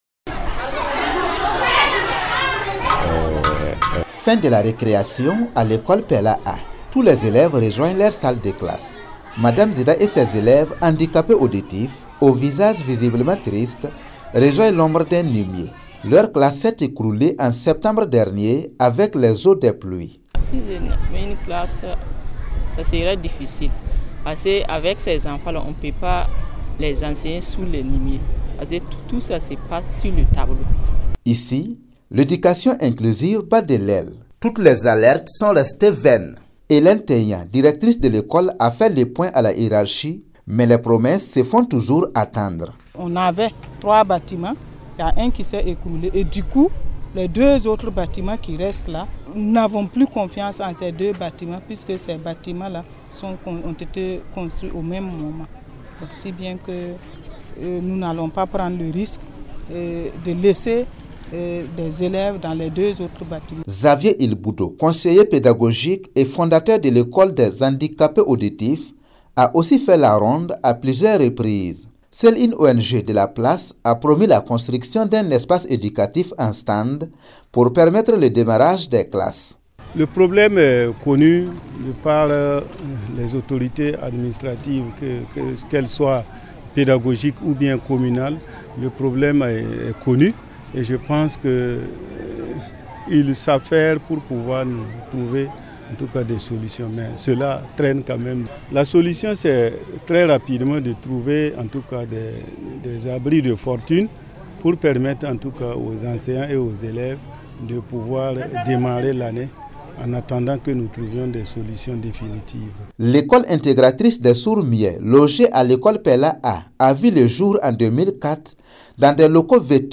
Depuis la rentrée, les enseignants et les élèves se retrouvent sous les arbres dans l’attente d’un sauveur. Suivez le reportage